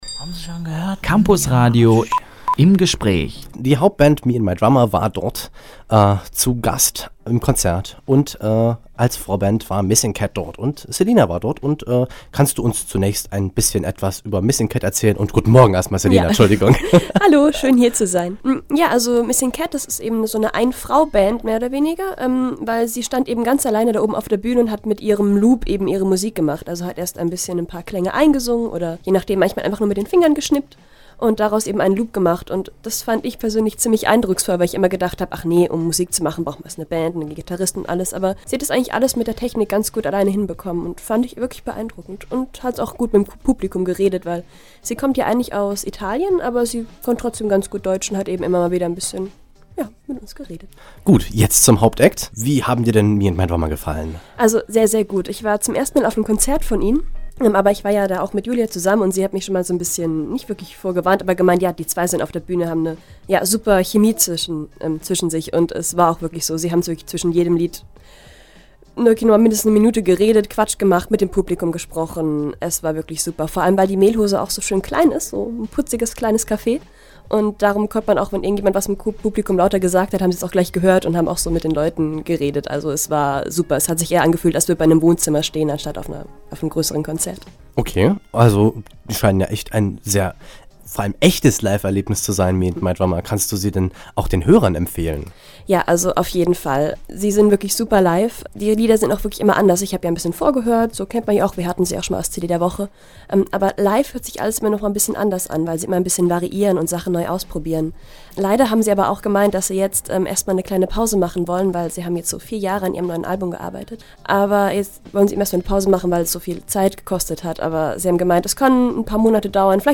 Konzertrezension: Me and My Drummer – Campusradio Jena